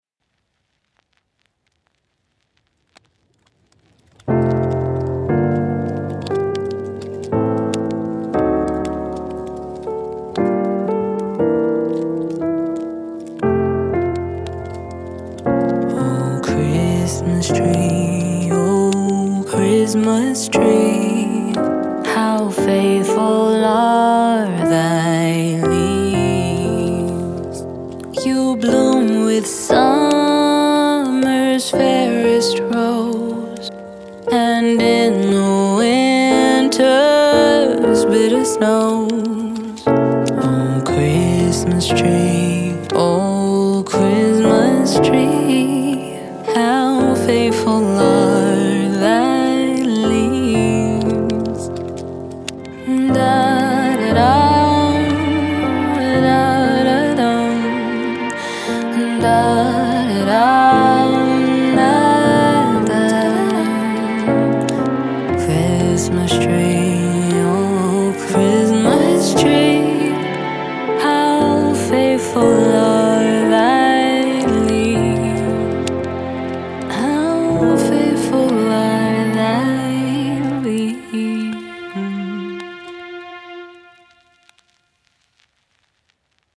Holiday, Jazz
F Maj